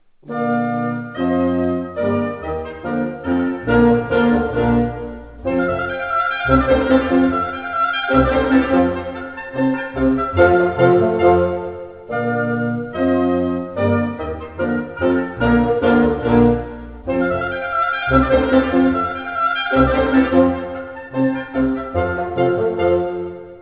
oboe
clarinet
french horn
bassoon
double bass)hu